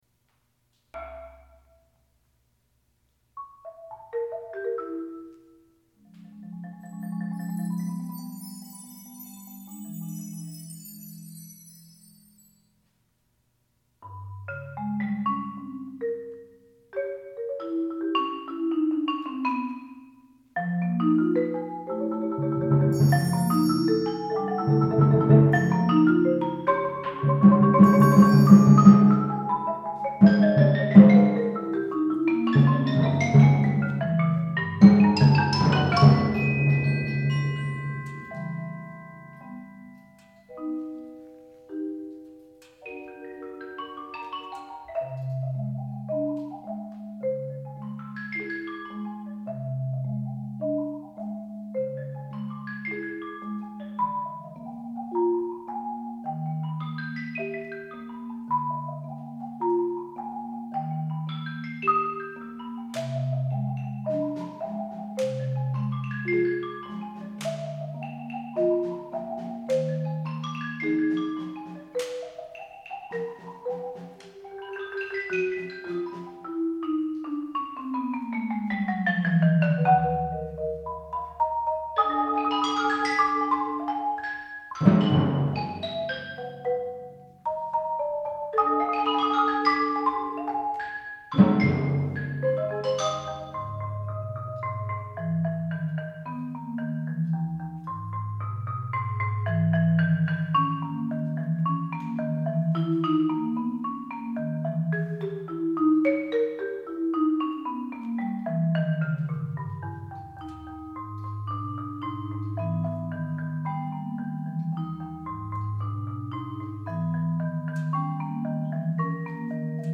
Genre: Percussion Ensemble
# of Players: 9 + Piano
Percussion 1 (bells, claves)
Percussion 2 (xylophone, triangle)
Percussion 3 (vibraphone, slapstick, brake drum)
Percussion 4 (5 octave marimba)
Percussion 9 (timpani, claves)
Piano